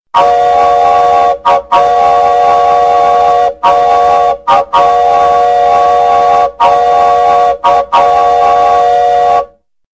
gudok-teplovoza_24561.mp3